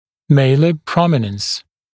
[ˈmeɪlə ‘prɔmɪnəns][ˈмэйлэ ‘проминэнс]выступание скул